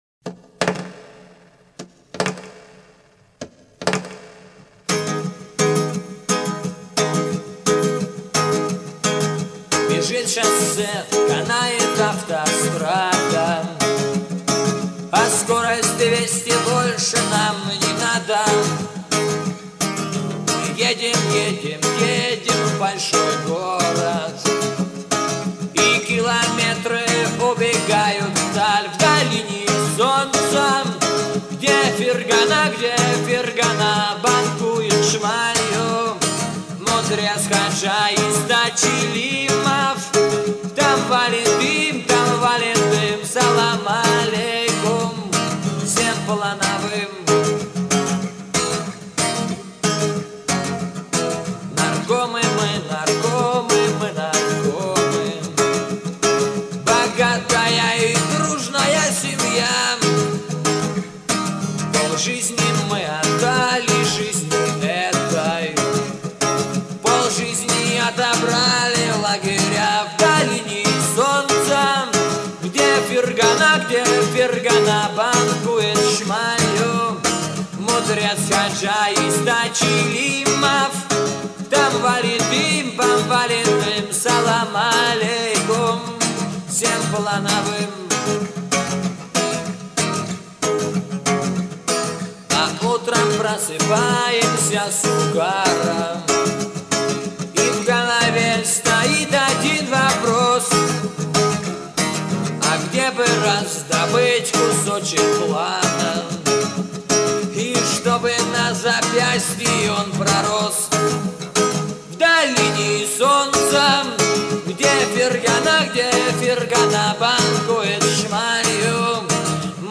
Главная » Файлы » Песни под гитару » Песни у костра